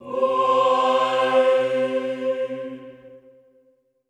Index of /90_sSampleCDs/Best Service - Extended Classical Choir/Partition D/HOO-AHH-EHH
HOO-AH  C3-R.wav